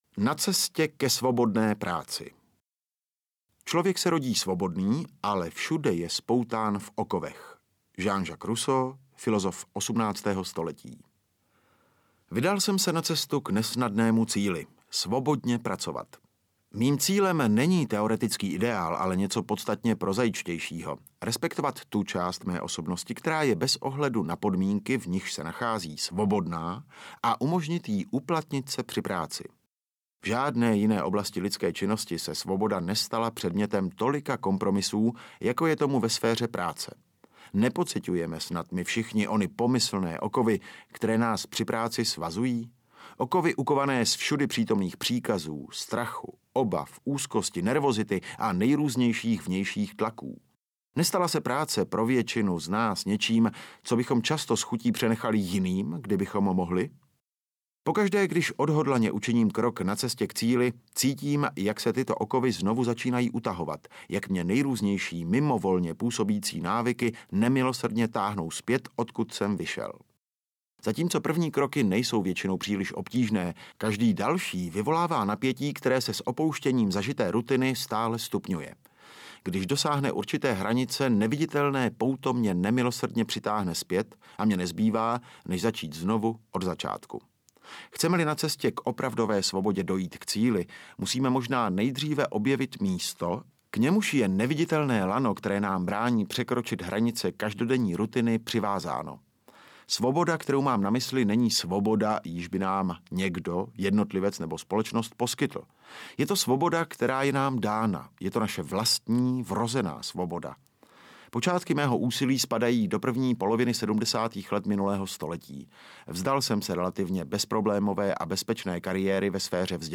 Audiokniha Inner Game pro manažery - Timothy Gallwey